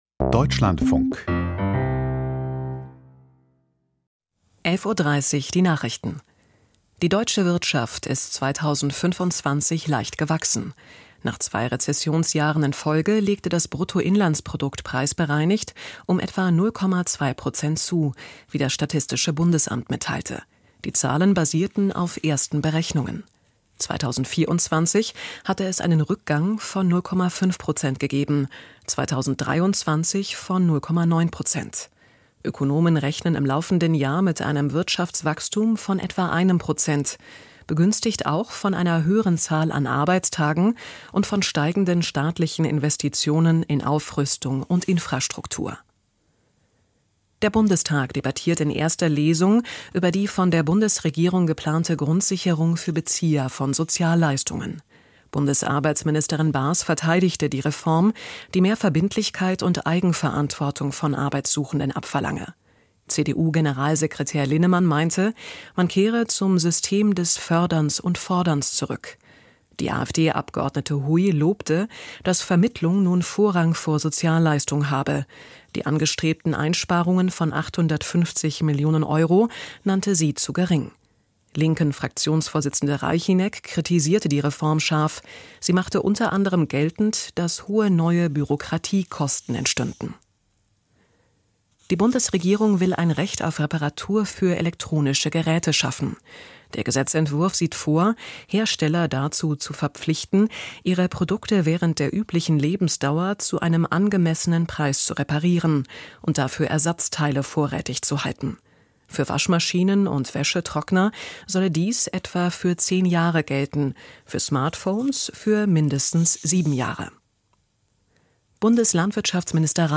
Die Nachrichten vom 15.01.2026, 11:30 Uhr